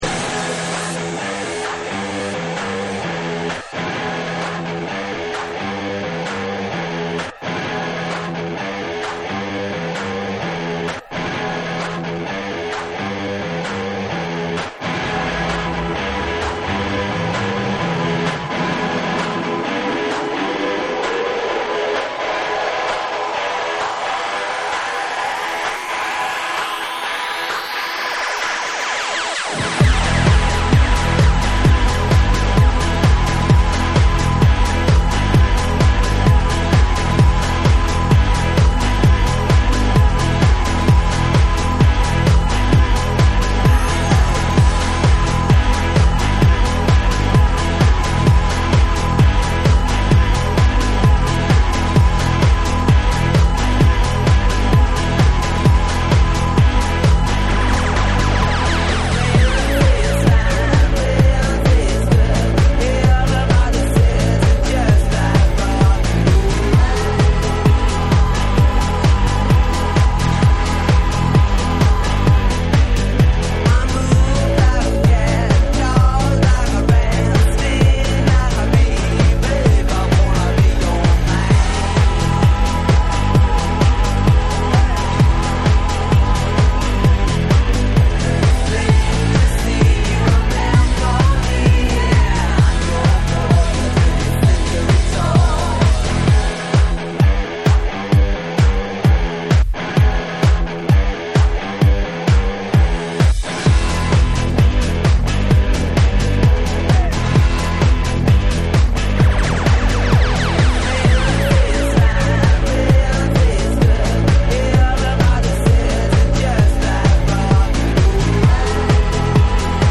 TECHNO & HOUSE / NEW WAVE & ROCK